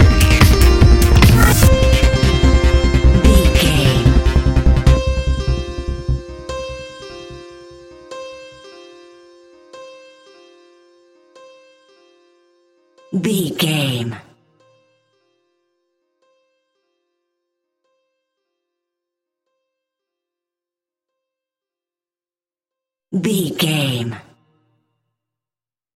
Epic / Action
Fast paced
Aeolian/Minor
Fast
groovy
uplifting
driving
energetic
repetitive
piano
drum machine
synthesiser
acid trance
synth leads
synth bass